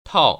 [tào] 타오